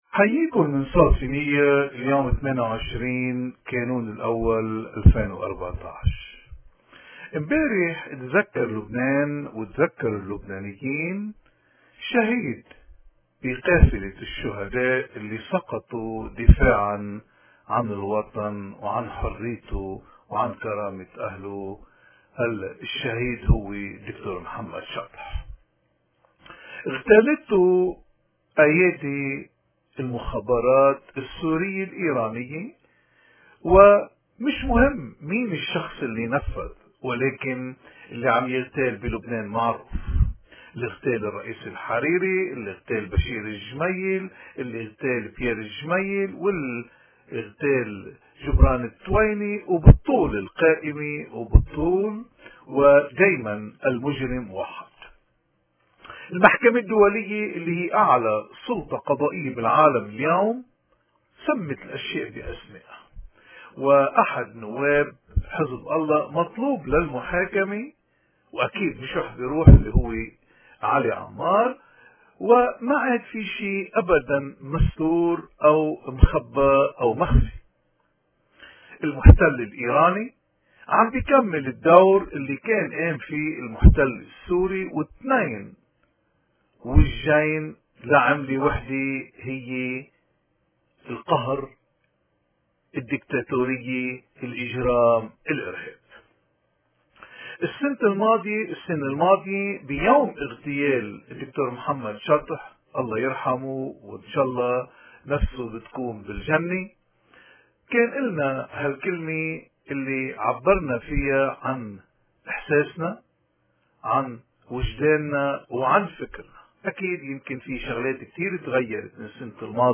قراءة ورثاء بمفهوم وطني وإيماني